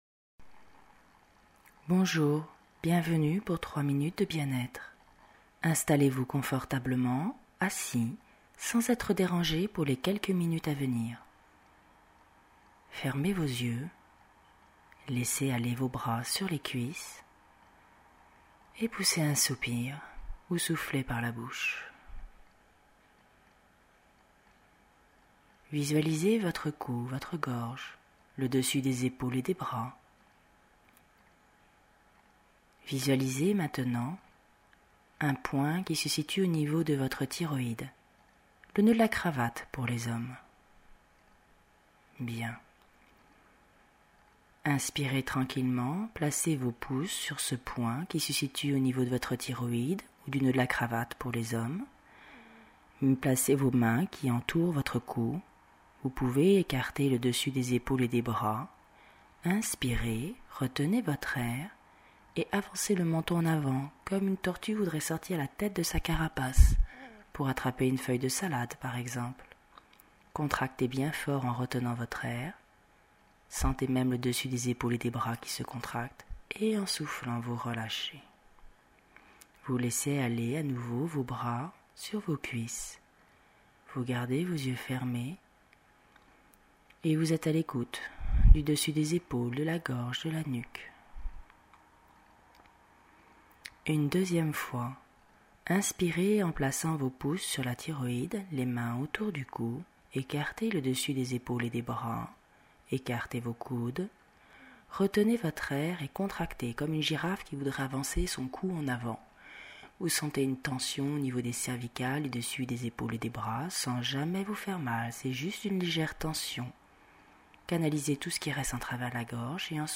Genre : sophro